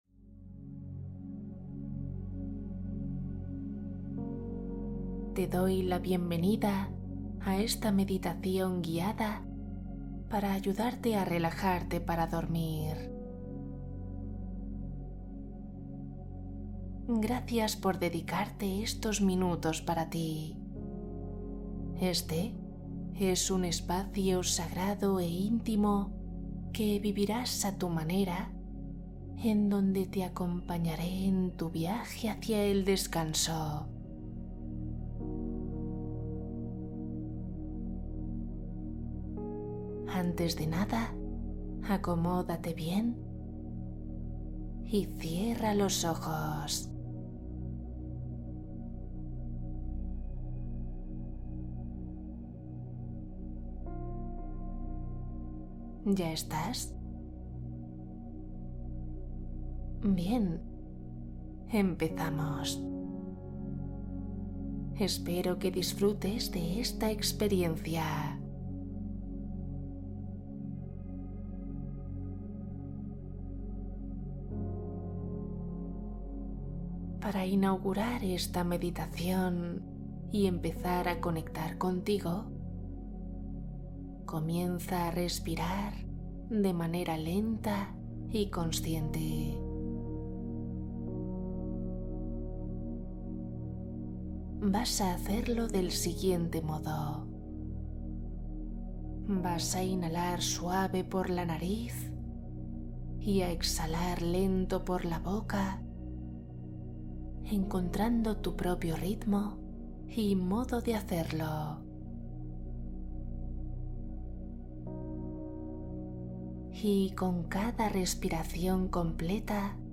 Sueño profundo y rápido Meditación guiada para descansar profundamente